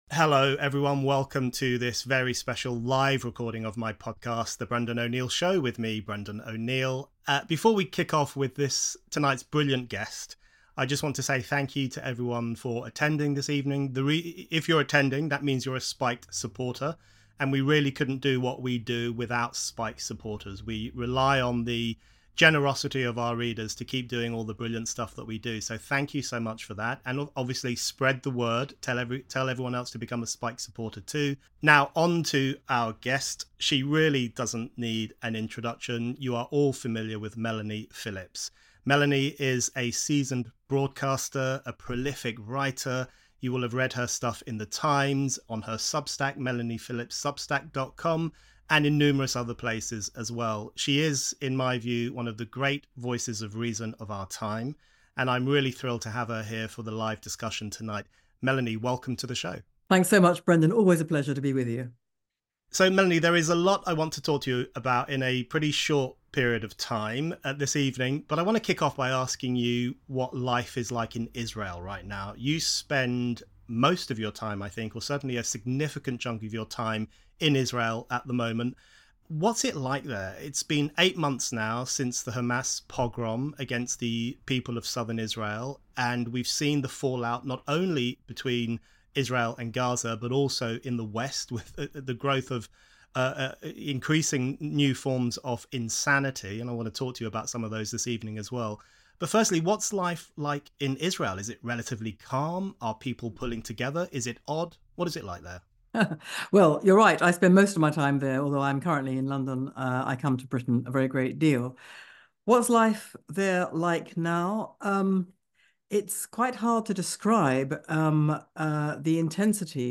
Times columnist Melanie Phillips returned to The Brendan O’Neill Show for this very special live episode.